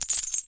snd_ui_money.wav